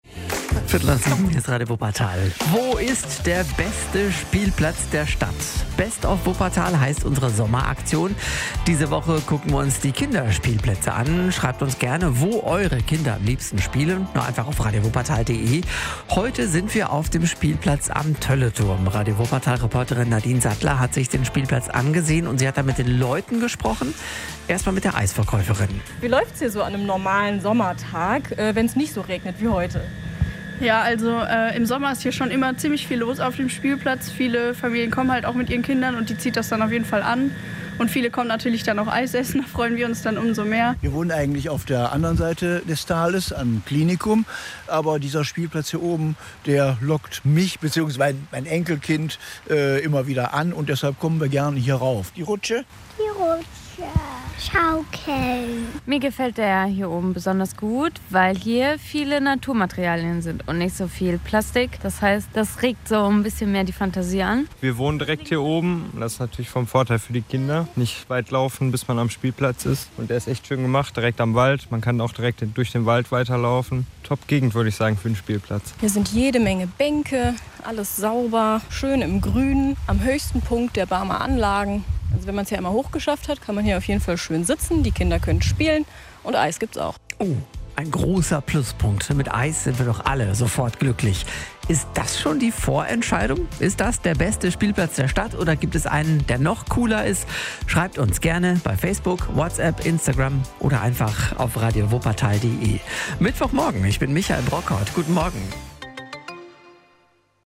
Spielplatz Toelleturm - Umfrage